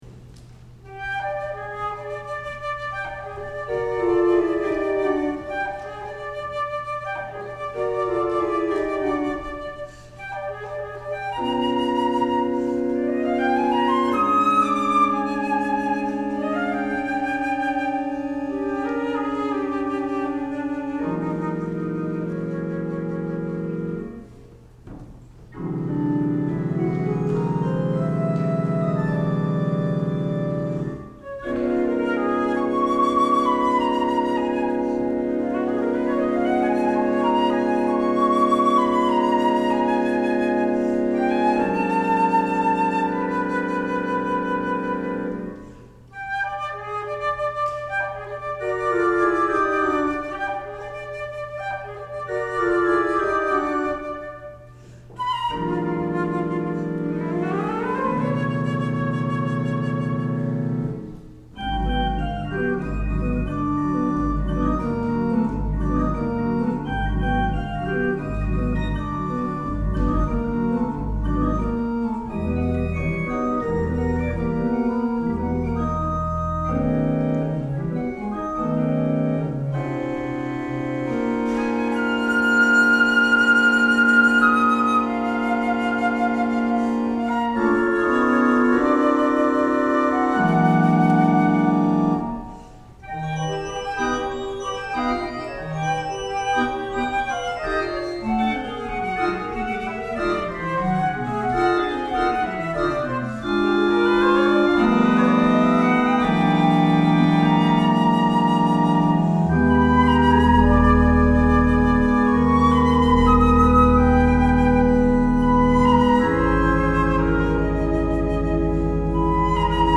for flute and organ